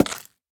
Minecraft Version Minecraft Version snapshot Latest Release | Latest Snapshot snapshot / assets / minecraft / sounds / item / axe / strip1.ogg Compare With Compare With Latest Release | Latest Snapshot